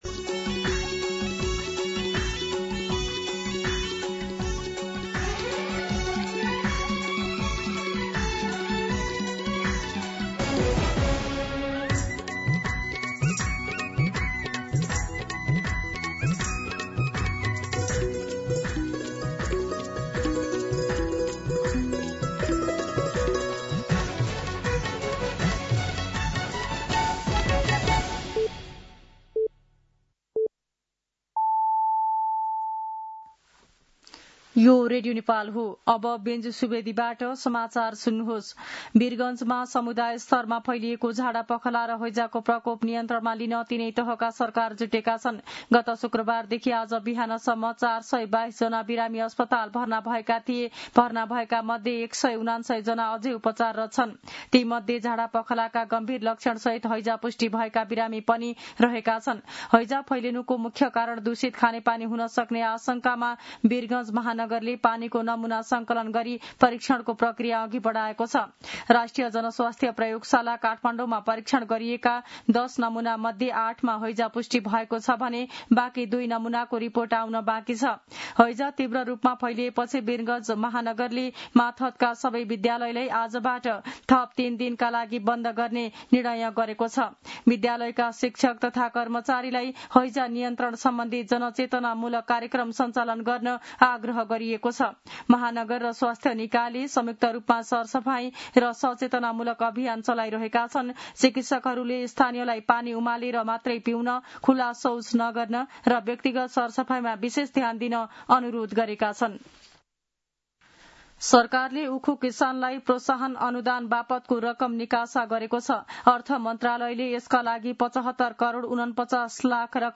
मध्यान्ह १२ बजेको नेपाली समाचार : ११ भदौ , २०८२